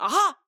VO_LVL3_EVENT_Aha echec_01.ogg